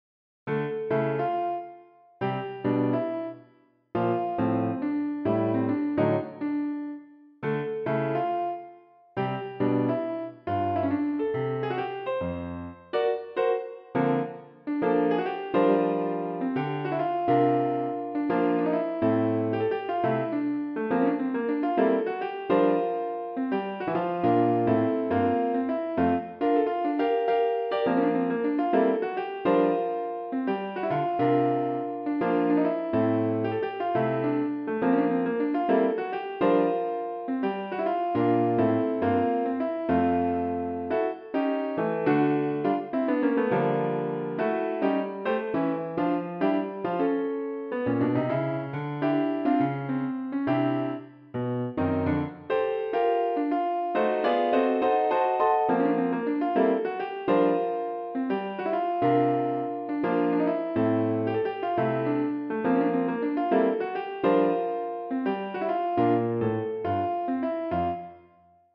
Difficulty = Intermediate